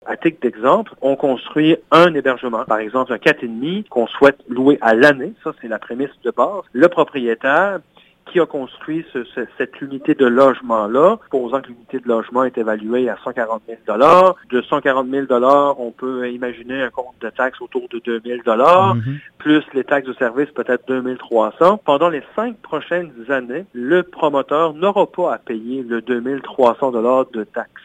La municipalité des Iles révélait mardi, en séance ordinaire du conseil, les grandes lignes d’un premier programme d’aide à la création de nouveaux logements locatifs disponibles à l’année,
Le maire, Jonathan Lapierre, explique que l’aide accordée, calculée sur la superficie des nouveaux logements locatifs, se fera par l’octroi d’un crédit de taxes foncières ainsi qu’un congé de taxes sur les services municipaux, et ce, pour une période de cinq ans.
CLIP-01-MUNICIPALITÉ-JONATHAN-LAPIERRE-CONSEIL14-JUILLET.mp3